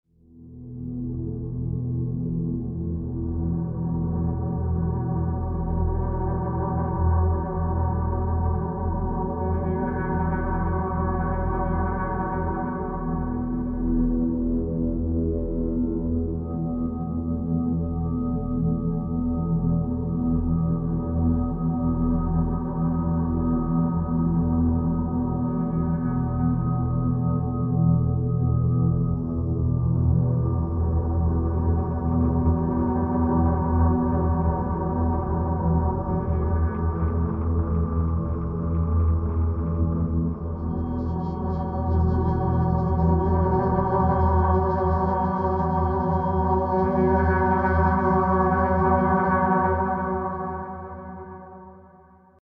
ohne Rhythmus